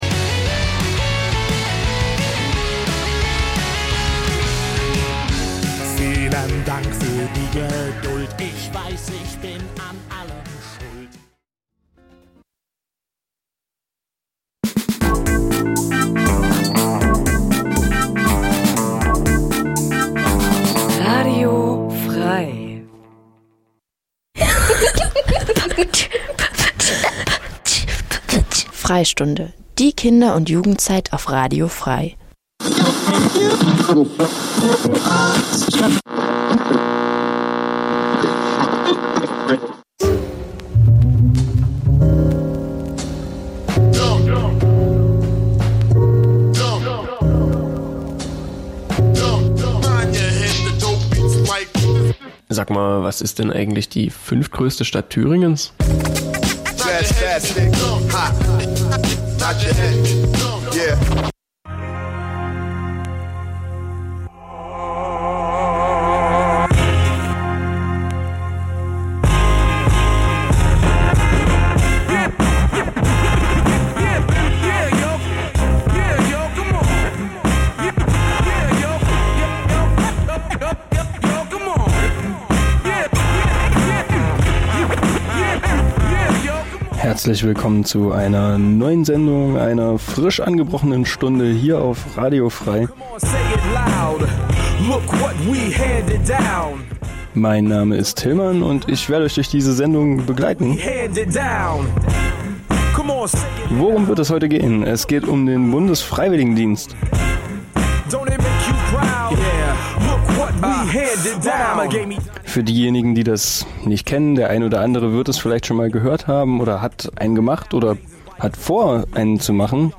Programm von Kindern und Jugendlichen für Kinder und Jugendliche Dein Browser kann kein HTML5-Audio.